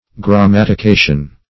Search Result for " grammatication" : The Collaborative International Dictionary of English v.0.48: Grammatication \Gram*mat"i*ca"tion\, n. A principle of grammar; a grammatical rule.